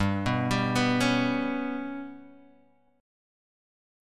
GMb5 Chord